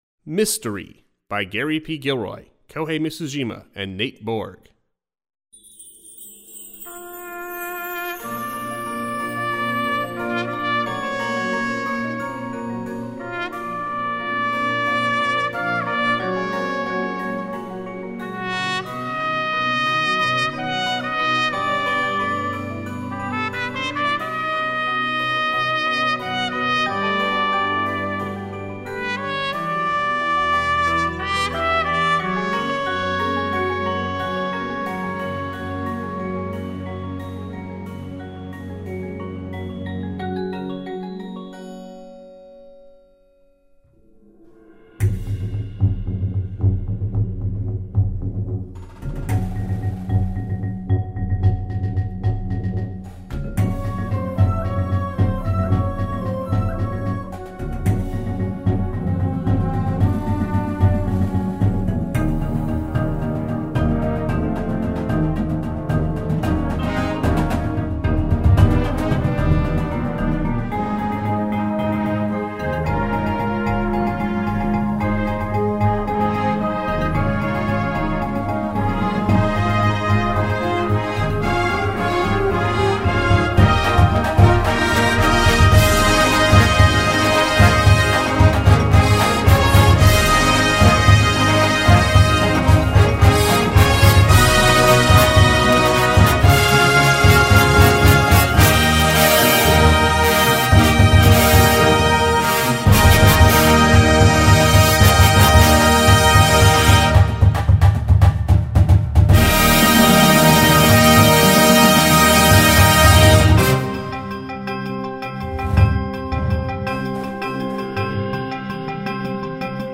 a contemporary Marching Band Show